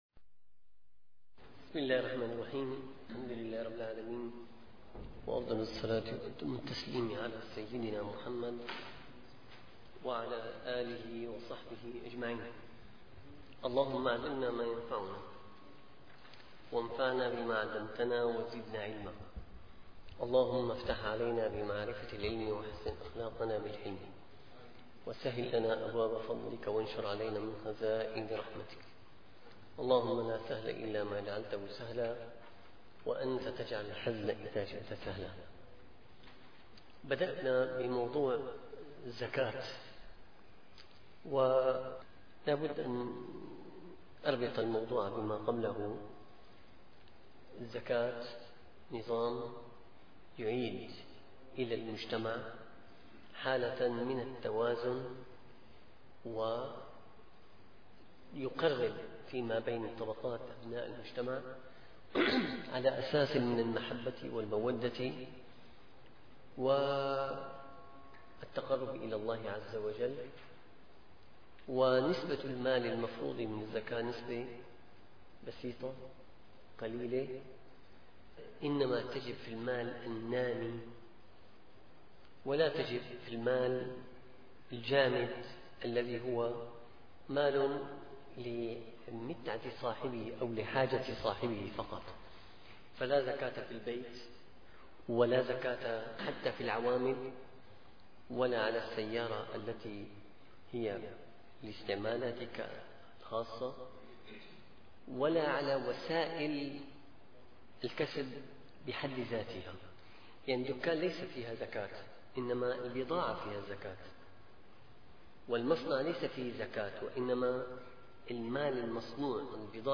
- الدروس العلمية - الفقه الشافعي - المنهاج القويم شرح المقدمة الحضرمية - فصل في زكاة النعم (ص353-358)